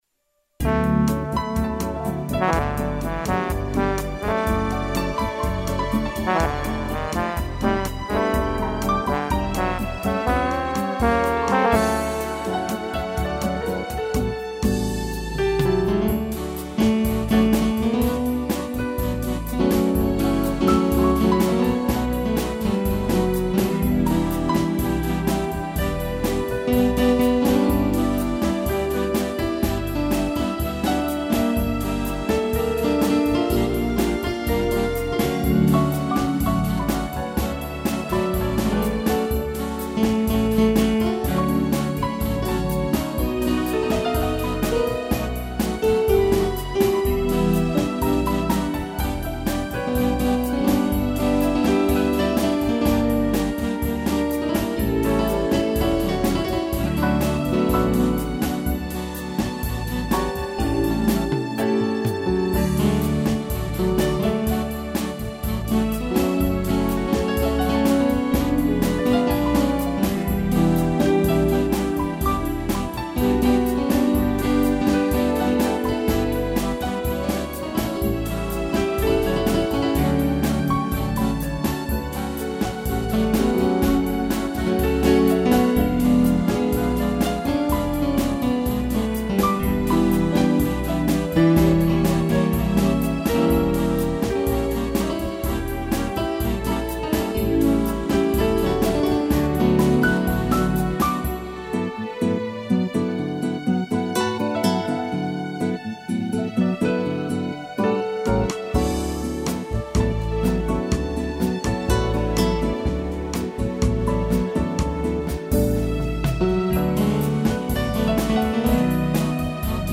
piano, trombone e strings
(instrumental)